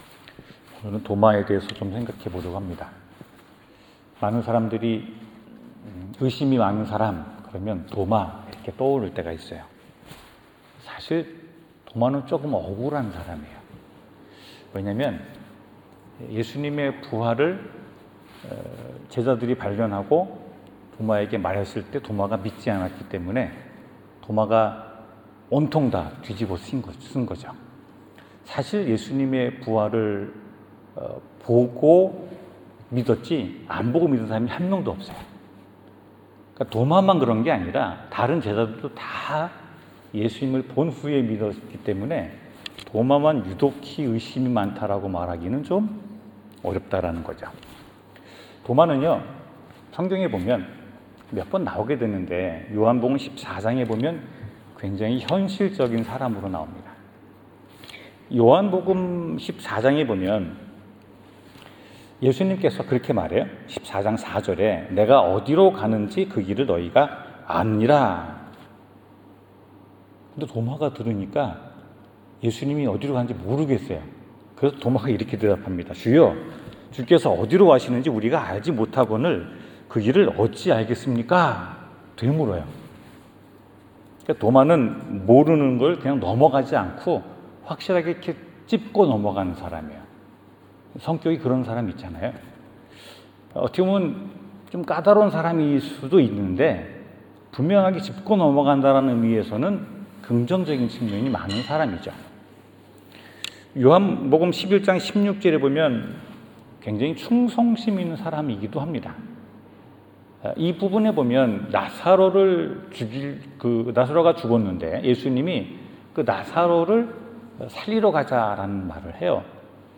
나의 주 나의 하나님 성경 : 요한복음 20:24-29절 설교